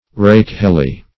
Search Result for " rakehelly" : The Collaborative International Dictionary of English v.0.48: Rakehell \Rake"hell`\, Rakehelly \Rake"hell`y\ (r[=a]k"h[e^]l`[y^]), a. Dissolute; wild; lewd; rakish.
rakehelly.mp3